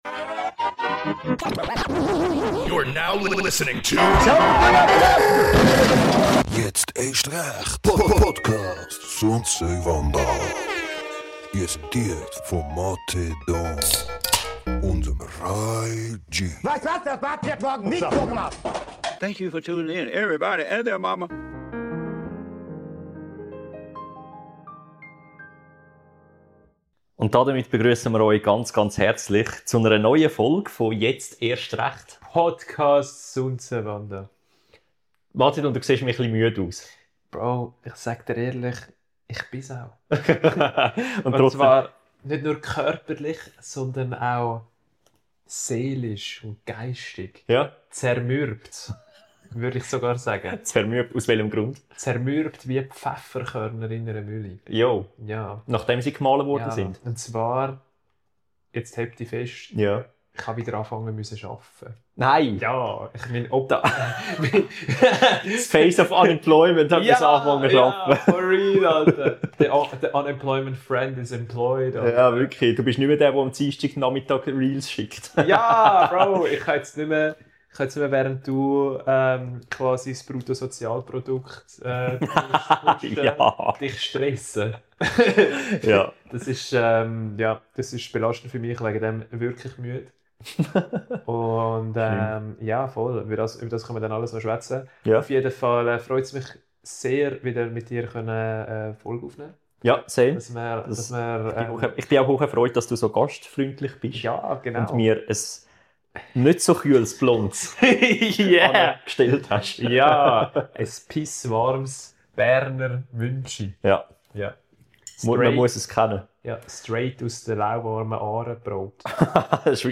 Die Folg het absolut kei Vorbereitig, kei besprocheni Theme, keis Schema und keis Muster.